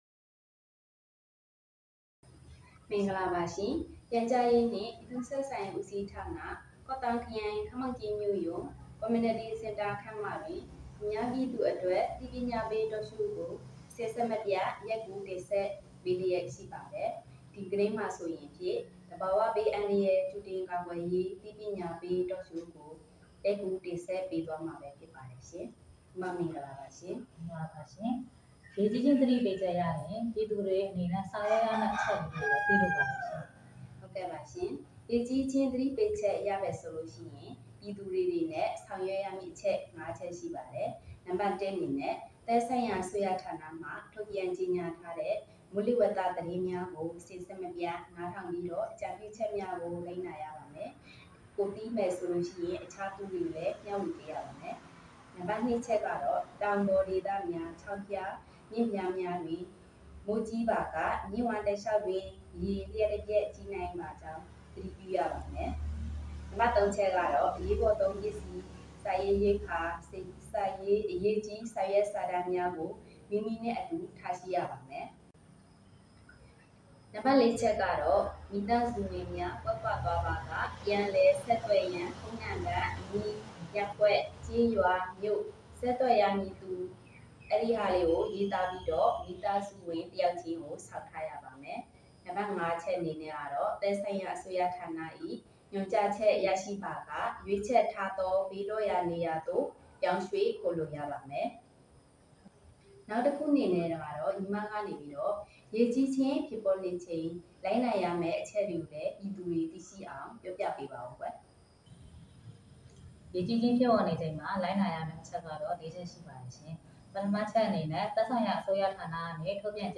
ခမောက်ကြီးမြို့Community Centre တွင် သဘာဝဘေးအန္တရာယ်ကြိုတင်ကာကွယ်ရေးအသိပညာပေးTalk Showပြုလုပ